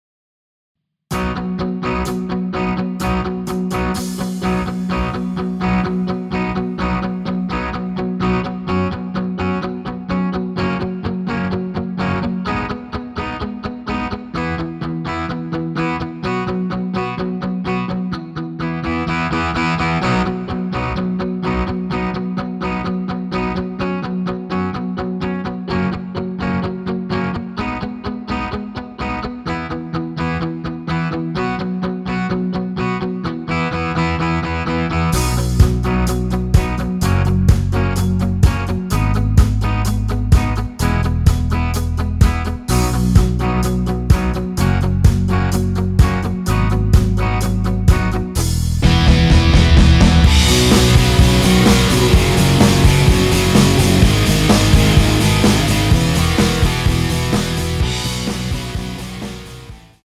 전주없이 노래 시작 하는 곡이라 전주 만들어 놓았습니다.
(하이 햇 소리 끝나고 노래 시작 하시면 됩니다.)(멜로디 MR 미리듣기 확인)
앞부분30초, 뒷부분30초씩 편집해서 올려 드리고 있습니다.